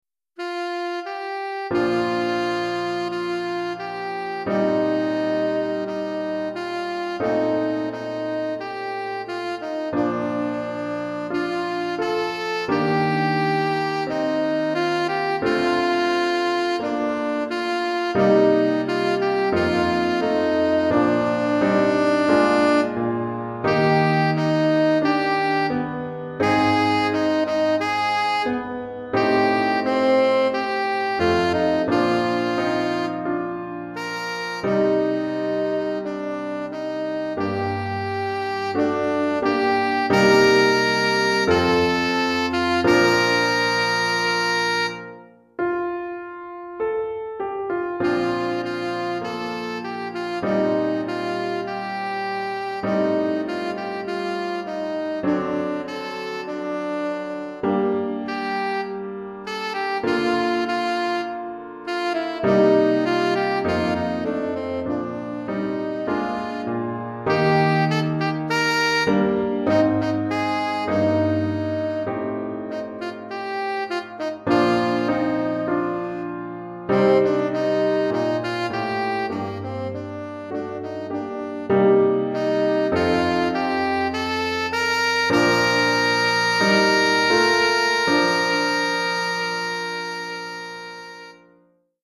Saxophone Soprano ou Saxophone Ténor et Piano